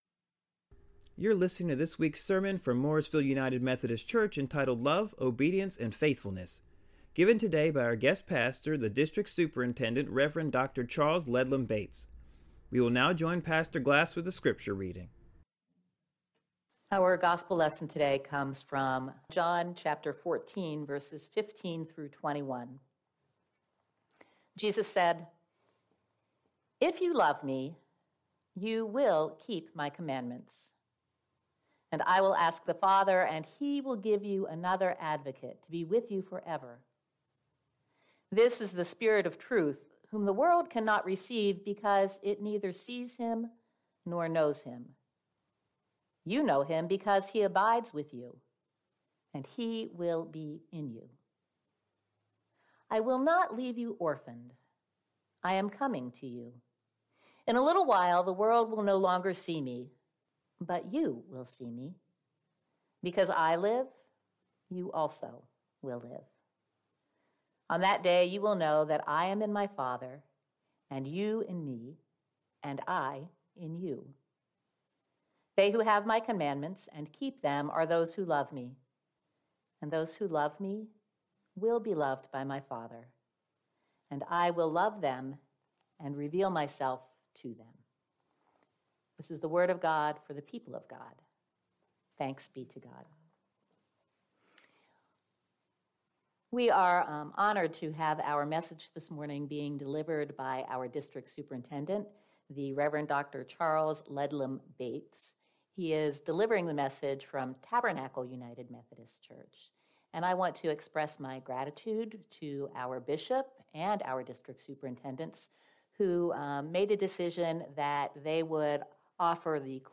Morrisville United Methodist Church Sermons